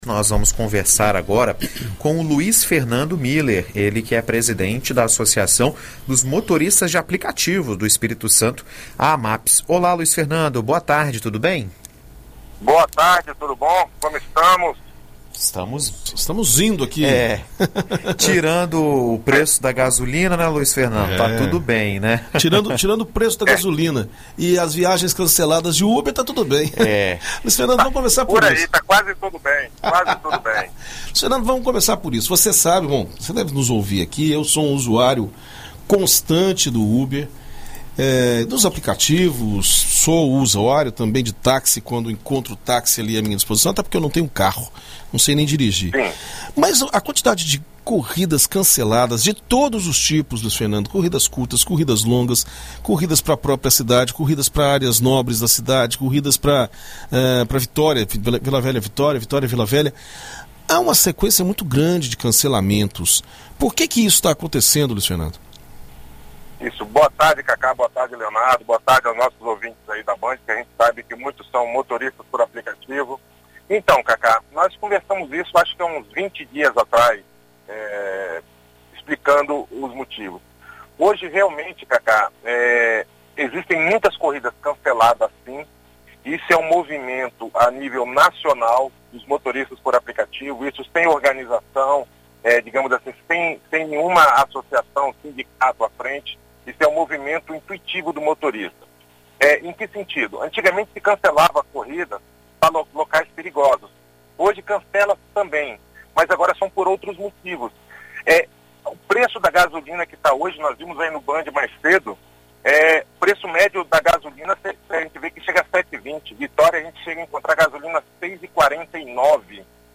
Em entrevista à BandNews FM Espírito Santo nesta terça-feira (14)